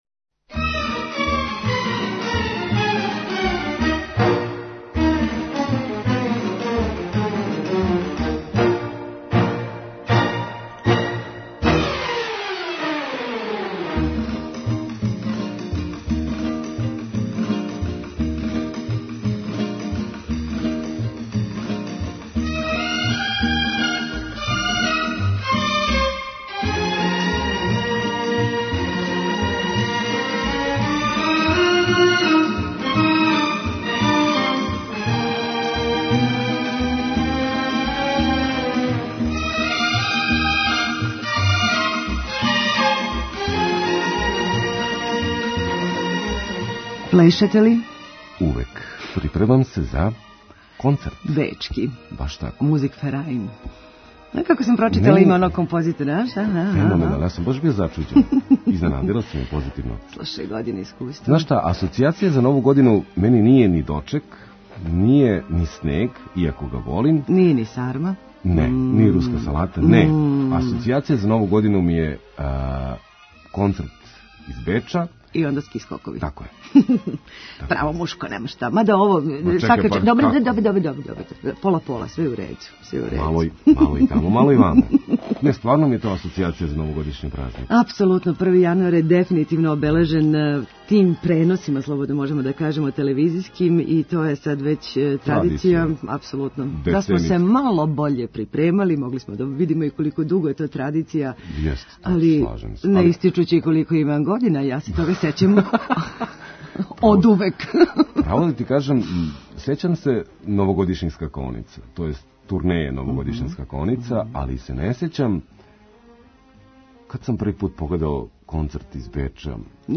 У сусрет манифестацији Улица Отвореног срца, наши репортери у центру Београда, Раковици и Сурчину прате последње припреме за овај догађај са хуманитарним карактером.